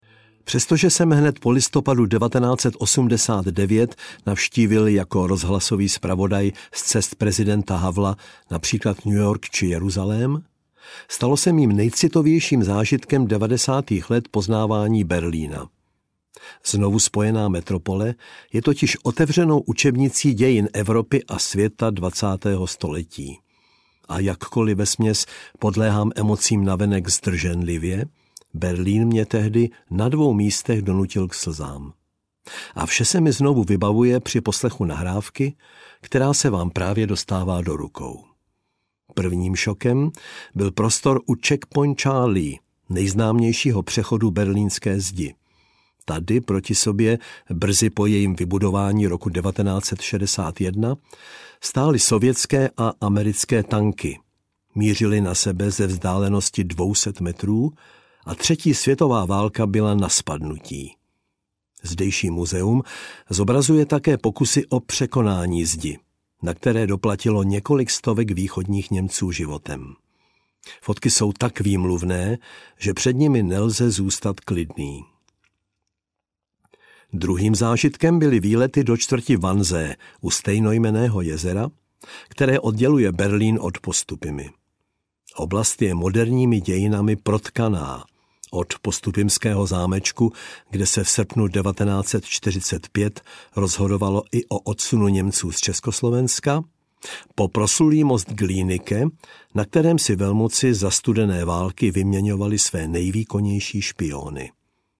Audiokniha
Čte: Miroslav Táborský